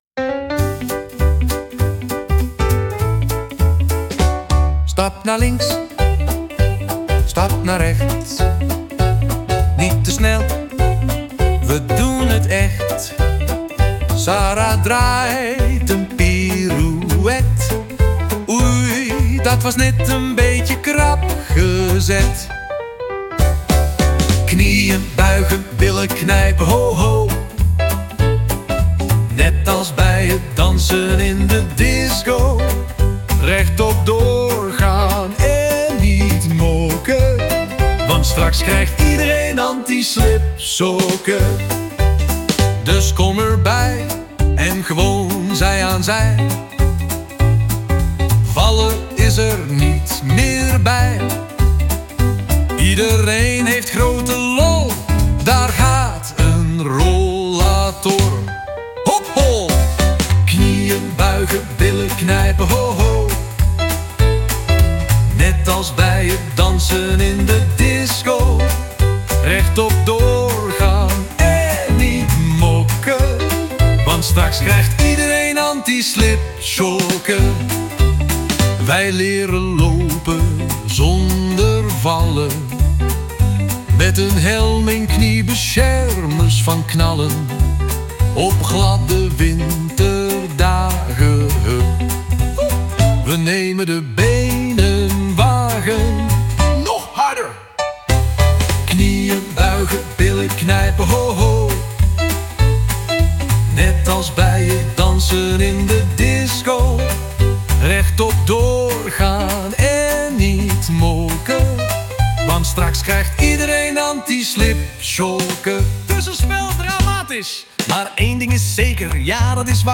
Zo maakte hij voor mij en de groep een vrolijk liedje over valpreventie en antislibsokken.